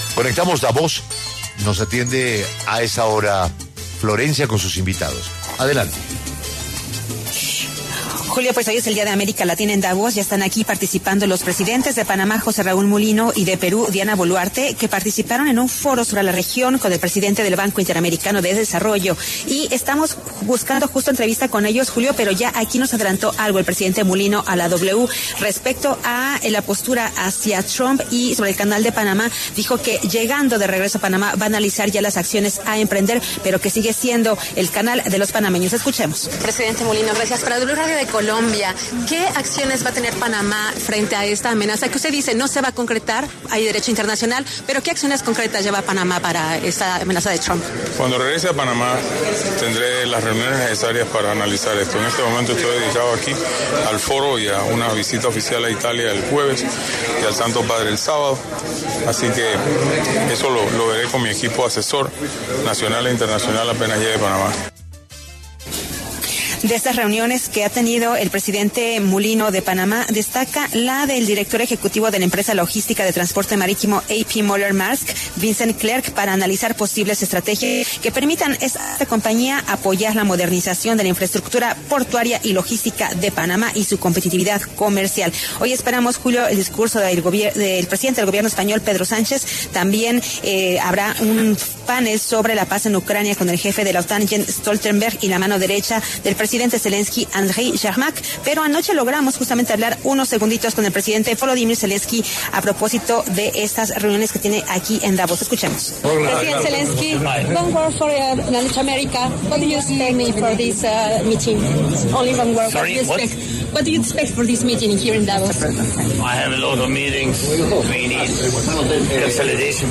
En medio del Foro de Davos, el presidente de Panamá, José Raúl Mulino, habló para La W sobre la postura de Donald Trump y el Canal de Panamá.